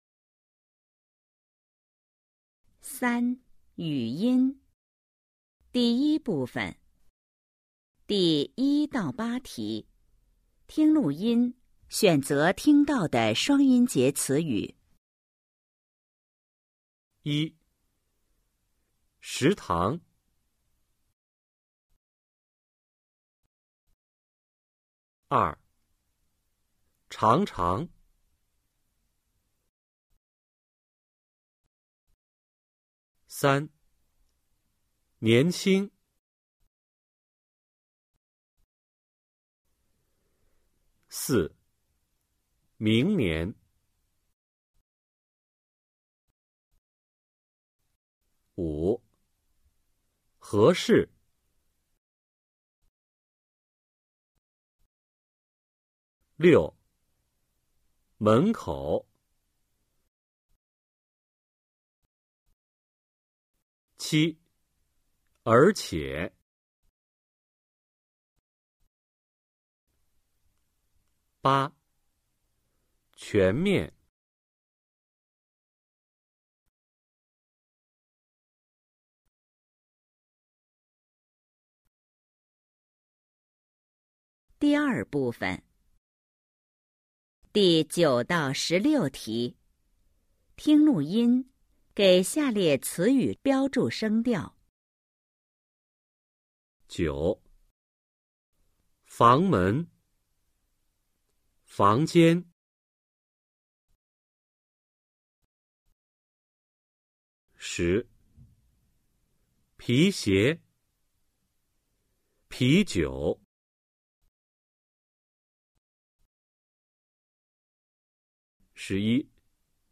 三、语音 Phần ngữ âm 🎧 7-2
Câu hỏi 1-8: Nghe bài ghi âm và đánh dấu vào các từ có hai âm tiết mà bạn nghe được.
Câu hỏi 9-16: Nghe bài ghi âm và đánh dấu vào từ ngữ không có vận mẫu uốn lưỡi trong mỗi nhóm.